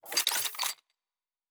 Weapon 11 Reload 3 (Rocket Launcher).wav